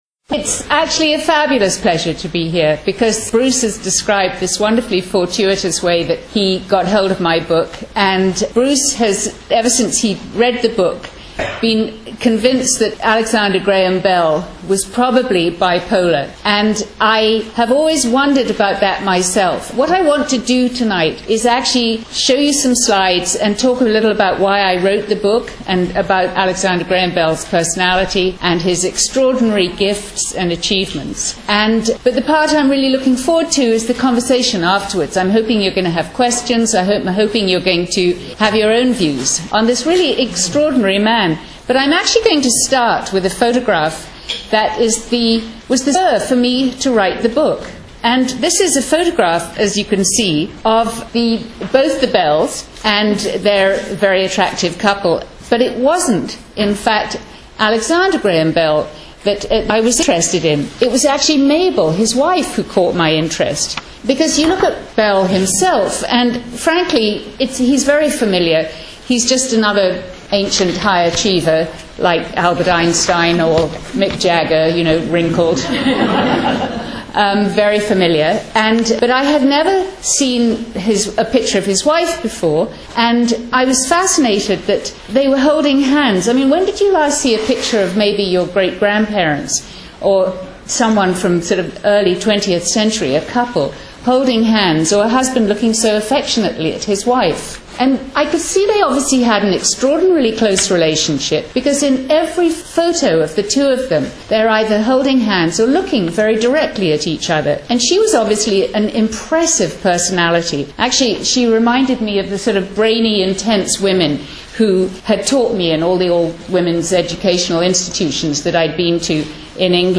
reading by author Charlotte Gray